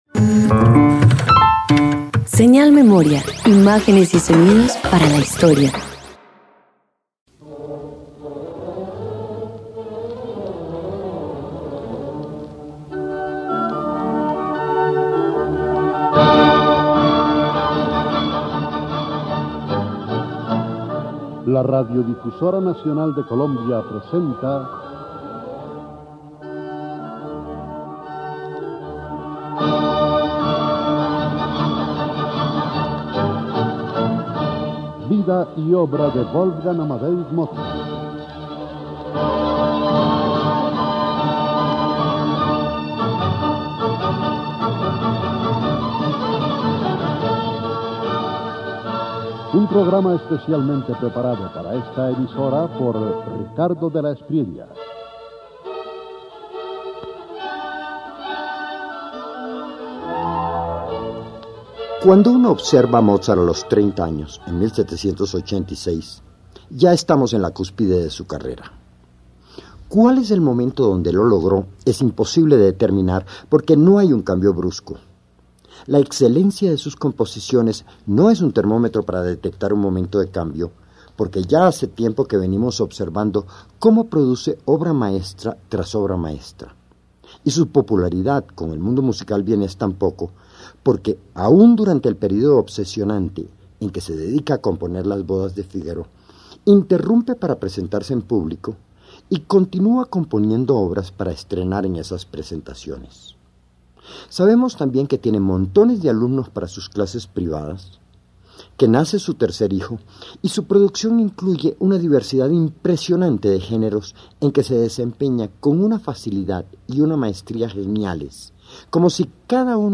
253 Obras para piano solo y cuatro manos_1.mp3